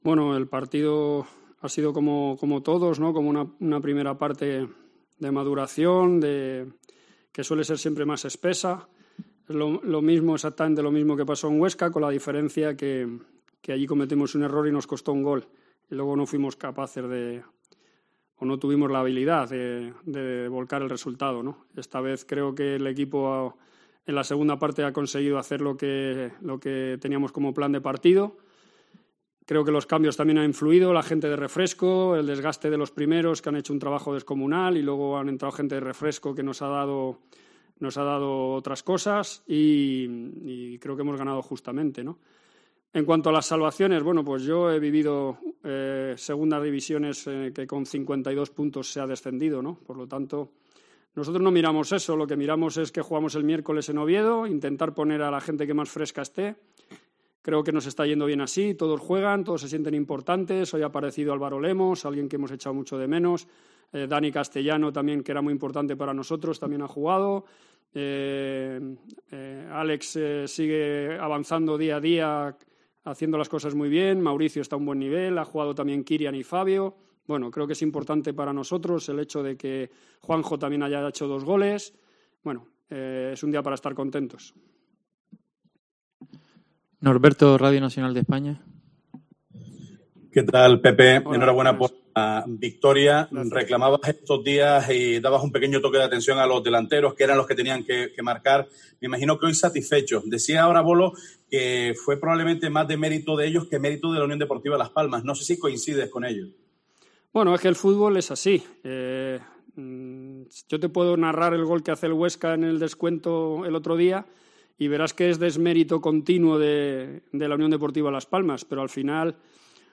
POSTPARTIDO
Escucha aquí las palabras del entrenador de la UD Las Palmas tras la victoria 3-0 en el estadio de Gran Canaria ante la Deportiva Ponferradina